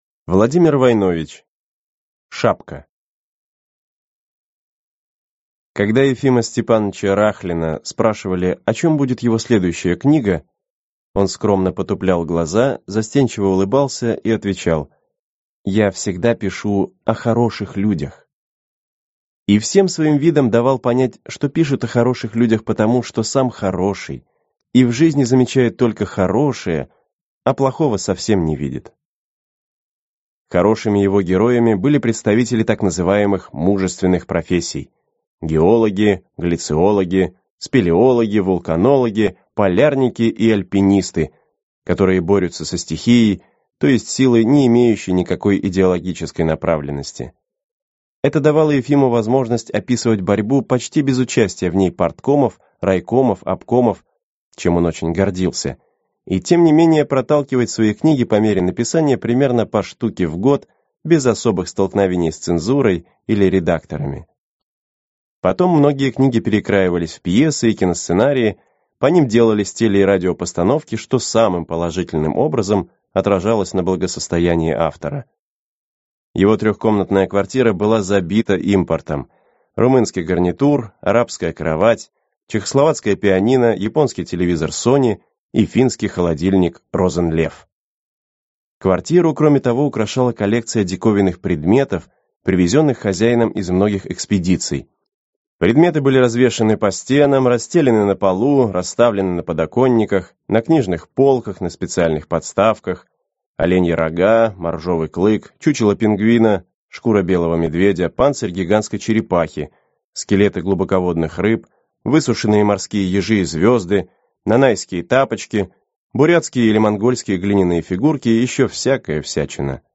Аудиокнига Шапка. Два товарища | Библиотека аудиокниг